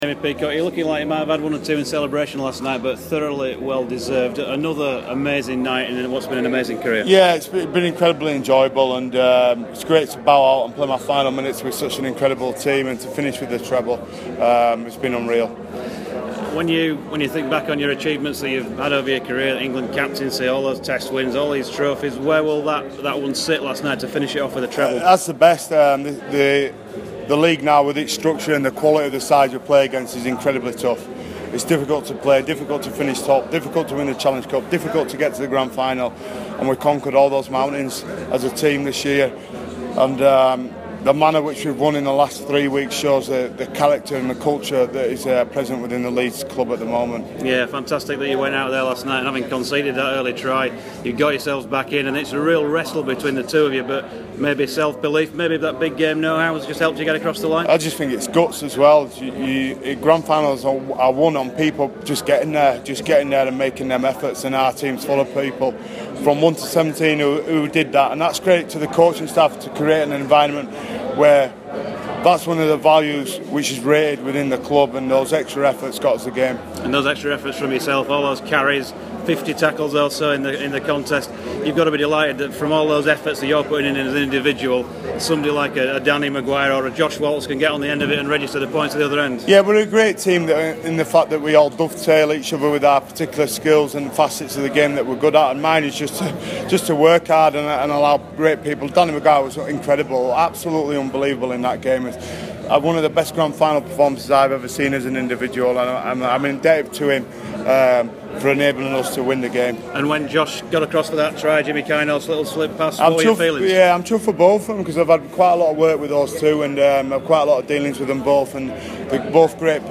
at Leeds Rhino's Grand final celebrations.